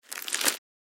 Crusty-bread-ripping-with-hands-2.mp3